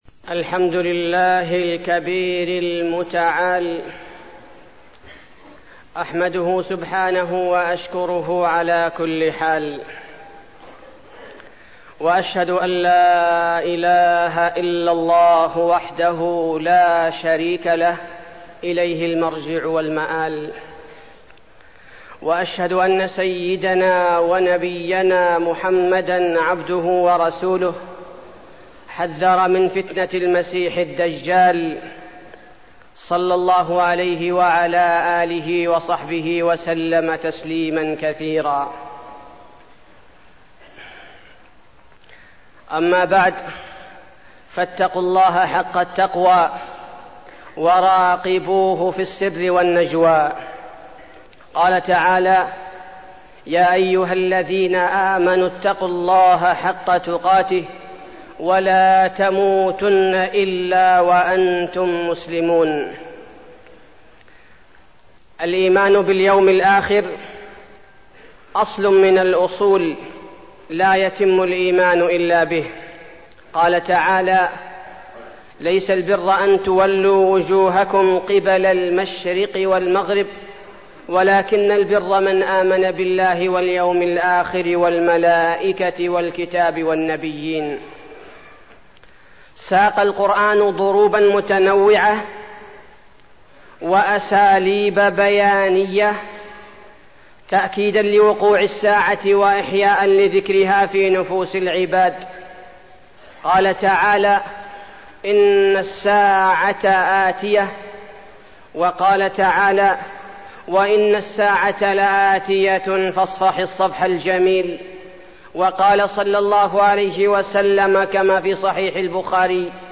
تاريخ النشر ٨ ذو القعدة ١٤١٨ هـ المكان: المسجد النبوي الشيخ: فضيلة الشيخ عبدالباري الثبيتي فضيلة الشيخ عبدالباري الثبيتي المسيح الدجال